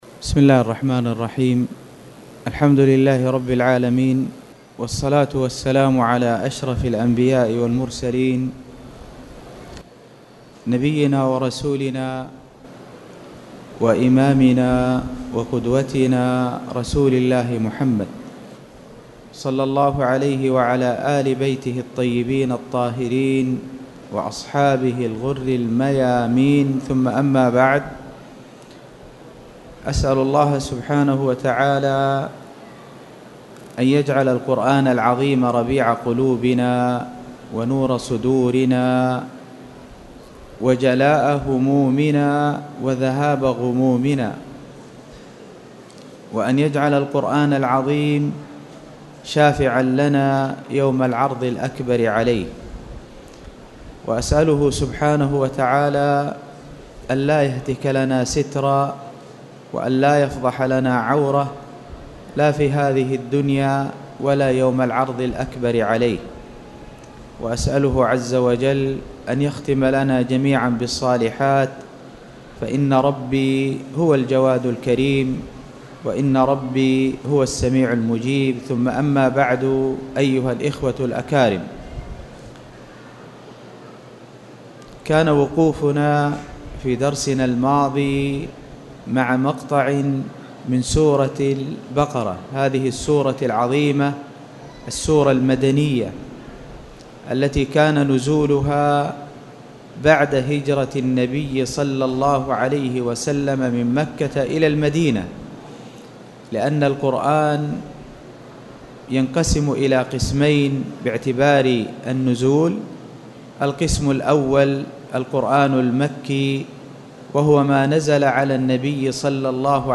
تاريخ النشر ٣٠ محرم ١٤٣٨ هـ المكان: المسجد الحرام الشيخ